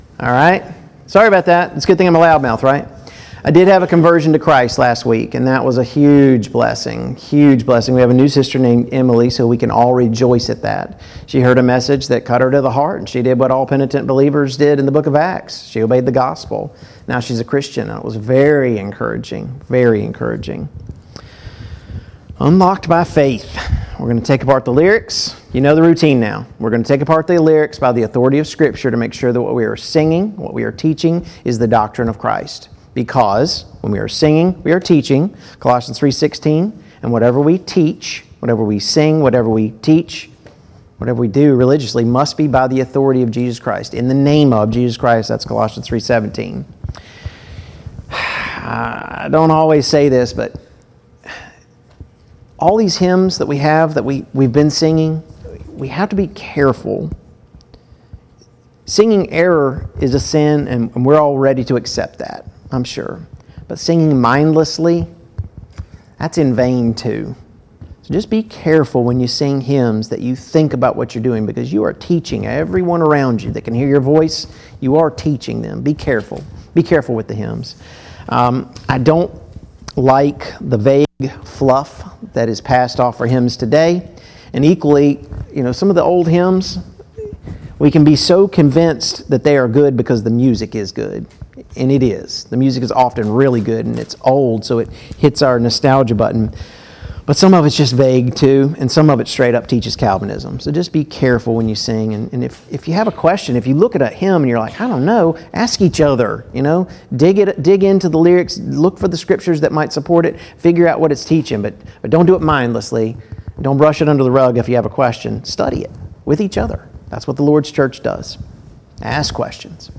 2022 Spring Gospel Meeting Service Type: Gospel Meeting Download Files Notes Topics: Faith , What is Biblical Faith?